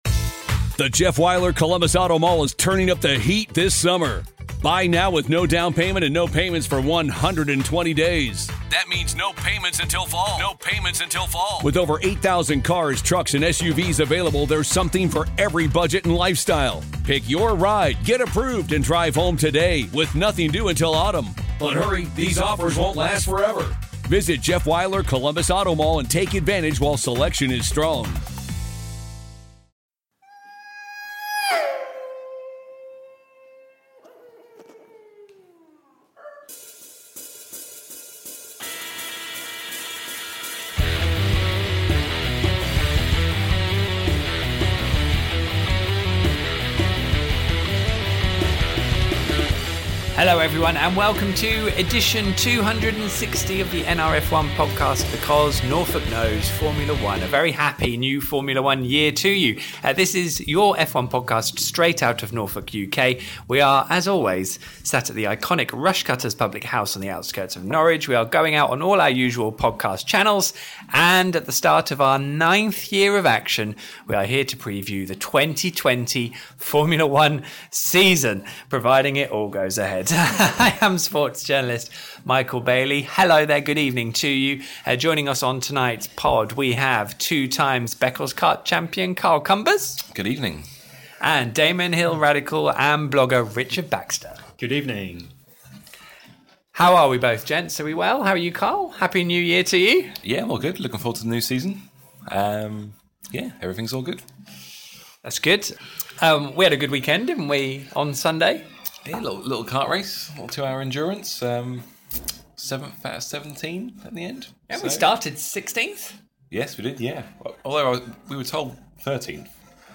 at The Rushcutters